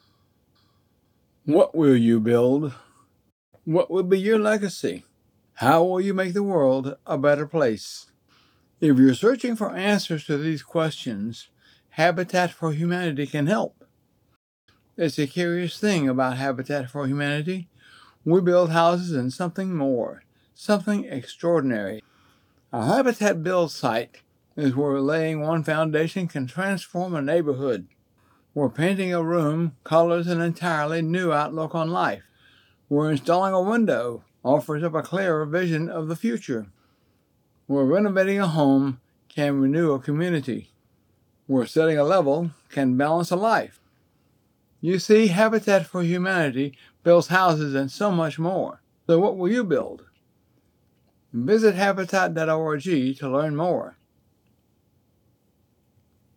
American Southern senior citizen looking to voice some projects fit for him
English - Southern U.S. English